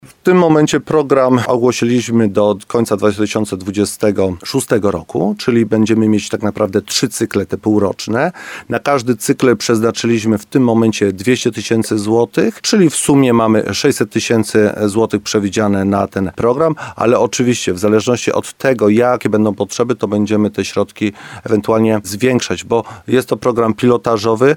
– Pierwszy nabór wniosków o wsparcie odbędzie się dopiero w listopadzie, ale już teraz osoby starsze powinny zbierać rachunki z apteki – mówi wójt Mirosław Cichorz.